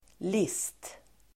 Uttal: [lis:t]